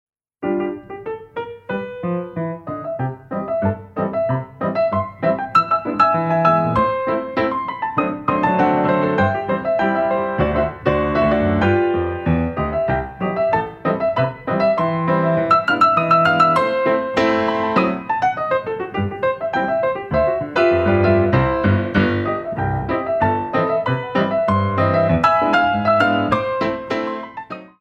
Compositions for Ballet Class
Dégagés en l'air